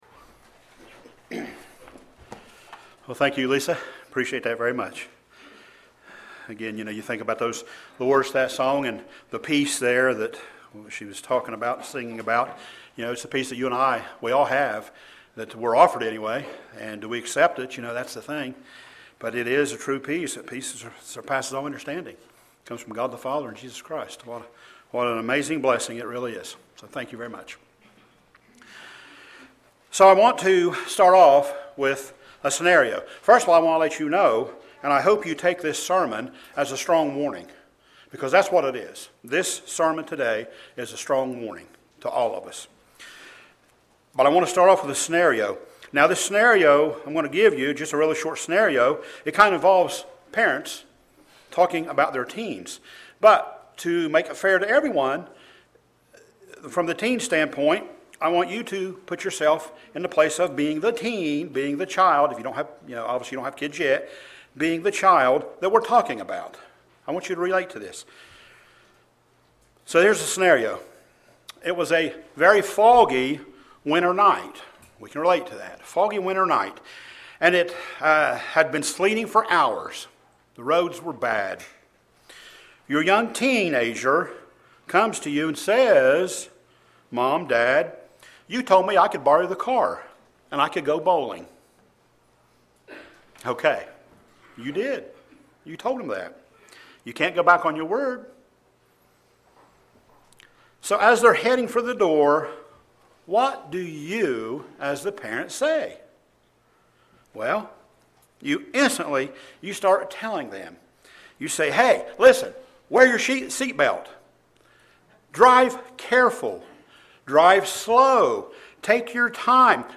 This sermon today is a strong warning.